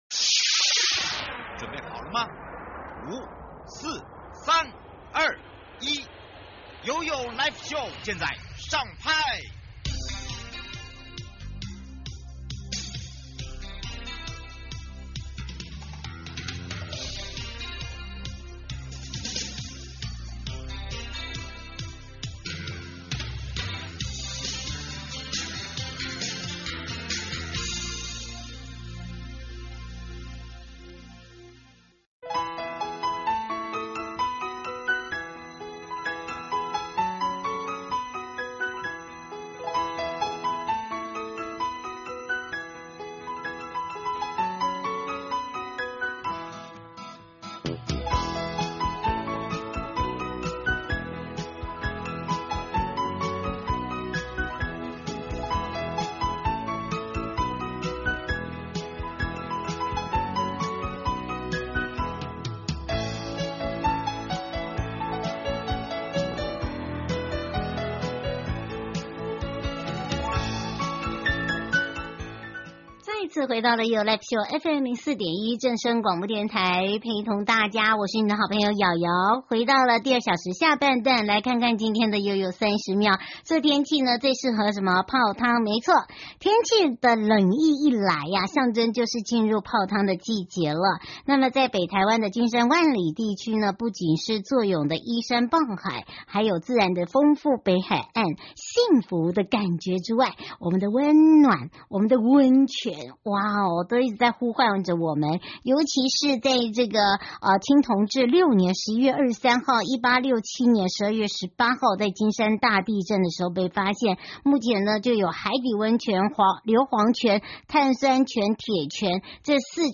找合法溫泉旅宿 就上臺灣旅宿網! 受訪者